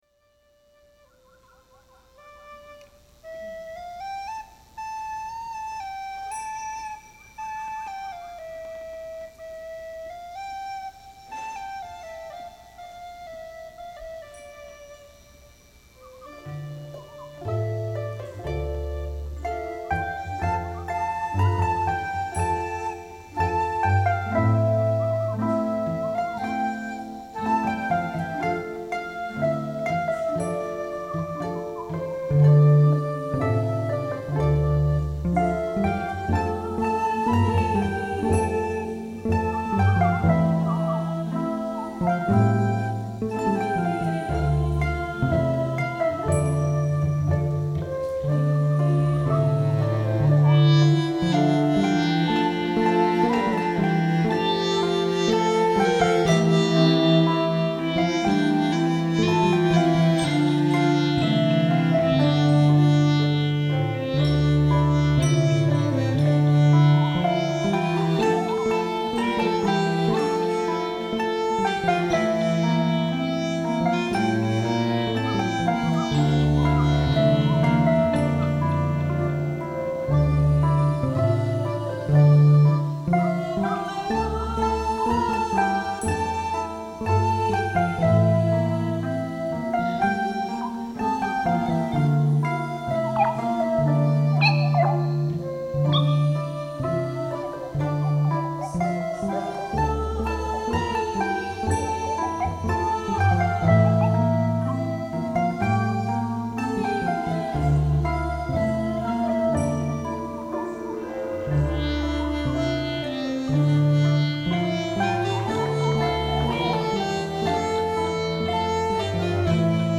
Live in Philadelphia, 2006